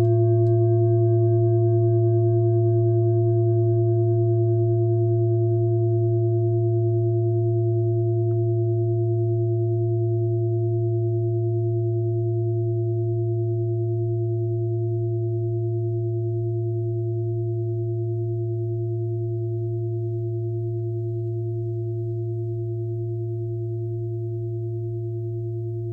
Klangschale Bengalen Nr.34
(Ermittelt mit dem Filzklöppel)
Diese Frequenz kann bei 224Hz hörbar gemacht werden, das ist in unserer Tonleiter nahe beim "A".
klangschale-ladakh-34.wav